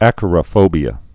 (ăkə-rə-fōbē-ə)